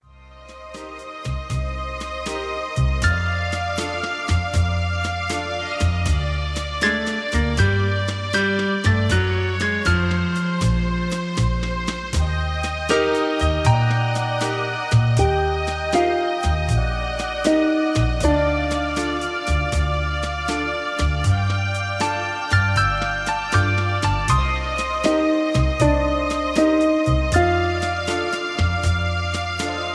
Key-Bb) Karaoke MP3 Backing Tracks
Just Plain & Simply "GREAT MUSIC" (No Lyrics).